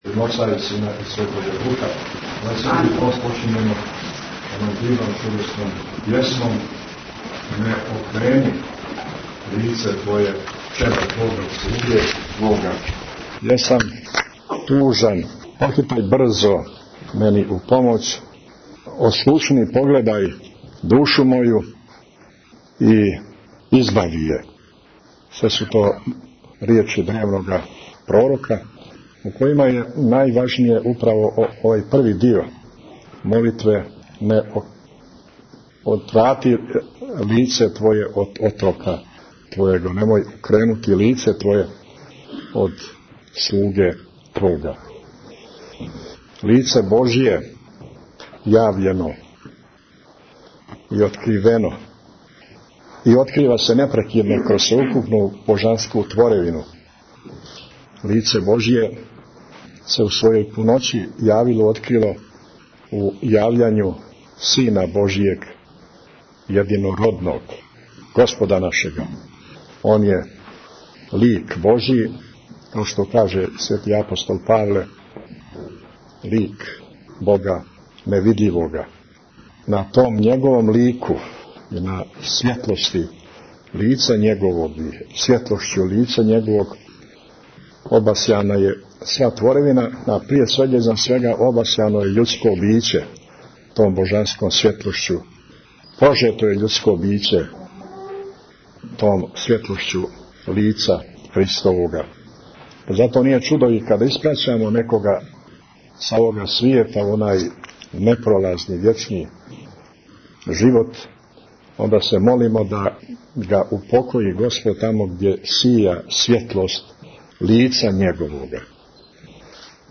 Вече опраштања у Цетињском манастиру Tagged: Бесједе 10:02 минута (1.73 МБ) Бесједа Његовог Високопреосвештенства Архиепископа Цетињског Митрополита Црногорско - приморског Г. Амфилохија изговорена на Вечерњој служби опраштања, којом почиње Часни пост, коју је у недјељу увече 2. марта 2014. године служио у Цетињском манастиру.